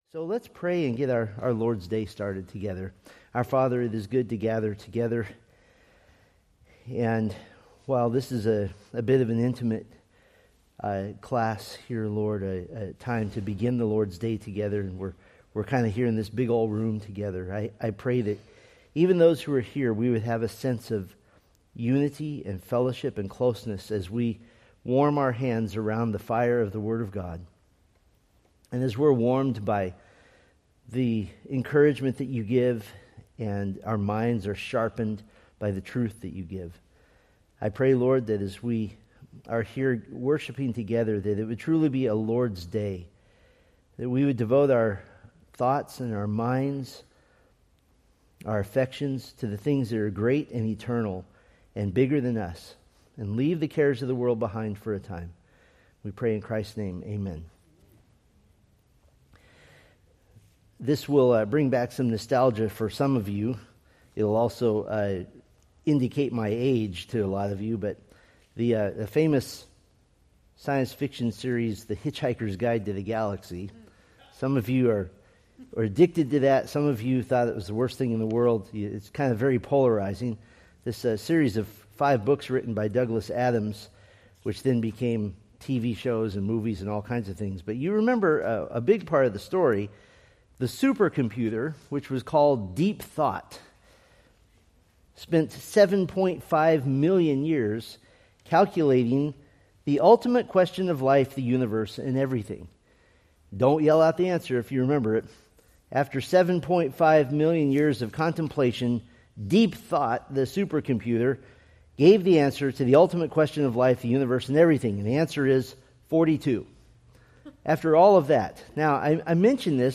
Date: Nov 9, 2025 Series: Psalms Grouping: Sunday School (Adult) More: Download MP3